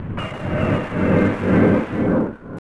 Panning Ring Modulated Sound FX (PCM) 58k
RingPan.wav